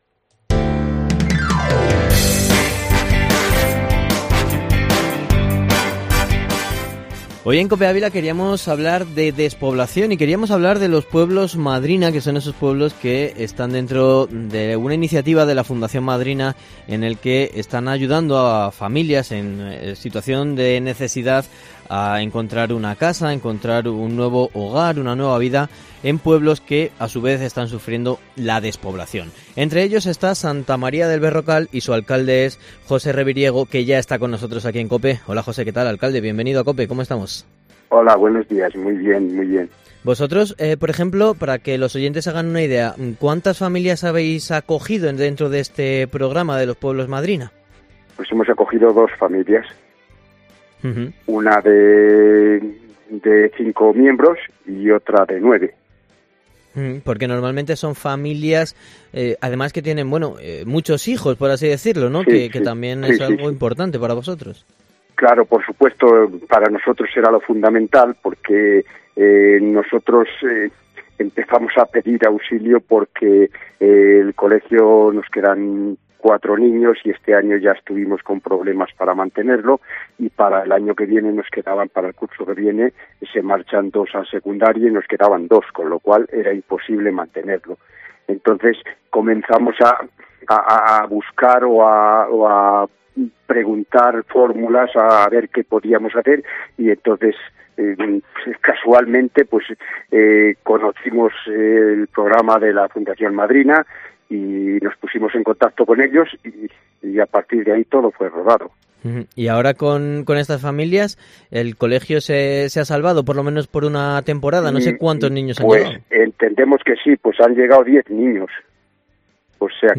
Entrevista en COPE Ávila con el alcalde de Santa María del Berrocal José Reviriego